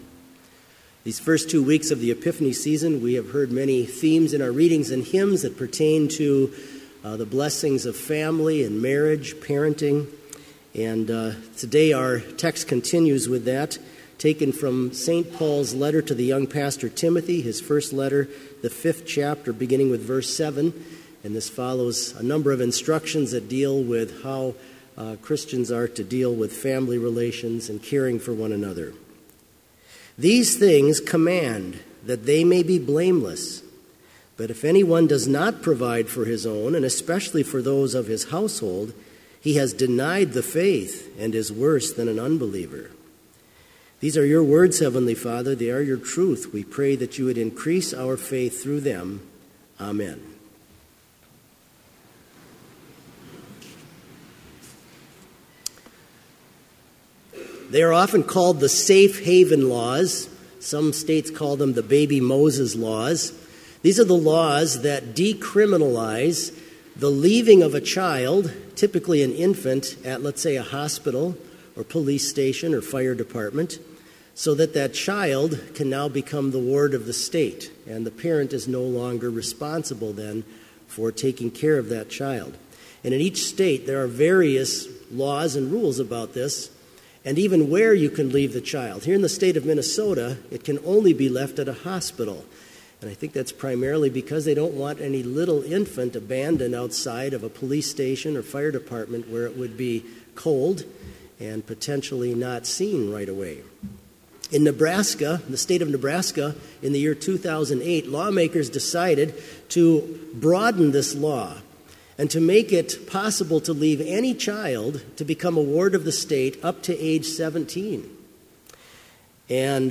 Complete service audio for Chapel - January 20, 2017